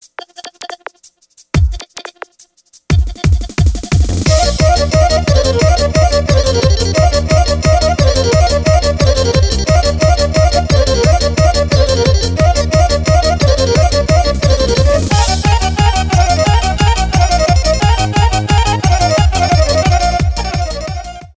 веселые , инструментальные
фолк
без слов
поп